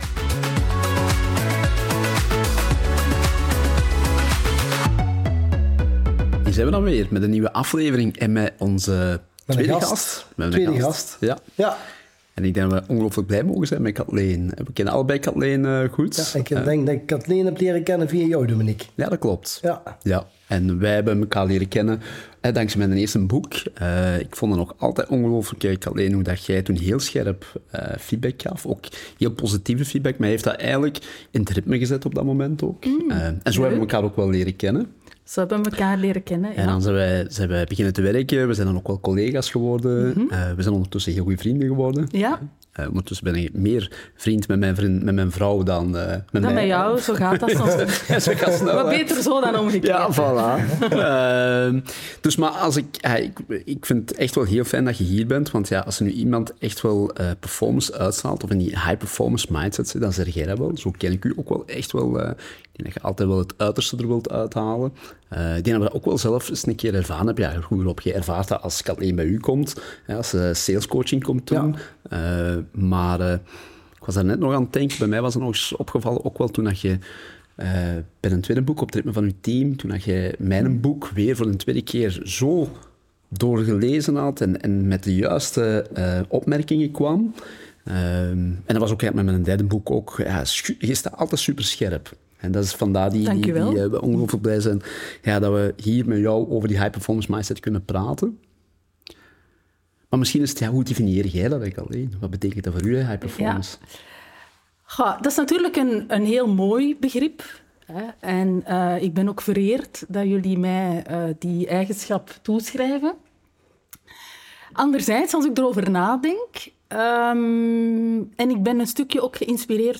Tweewekelijks brengen ze eerlijke gesprekken over persoonlijke ontwikkeling, gedrag en de mindset achter uitzonderlijke prestaties.